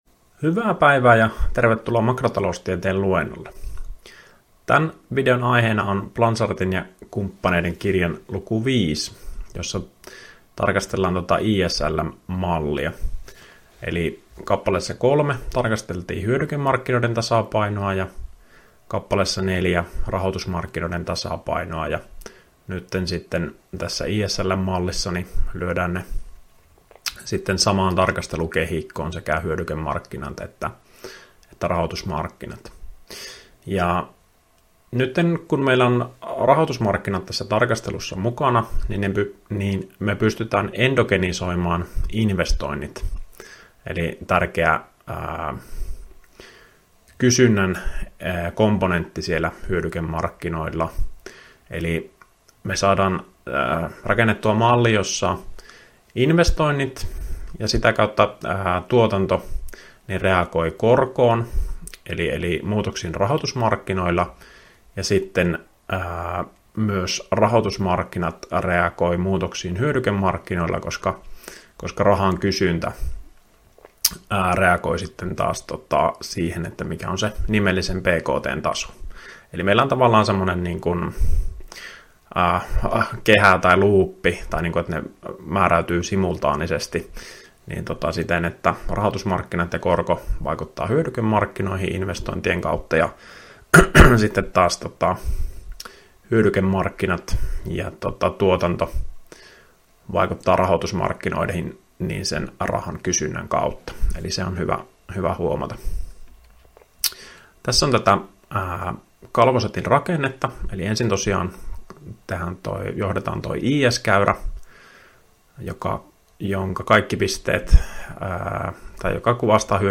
Opintojakson "Makrotaloustiede I" suhdanneosion 4. opetusvideo.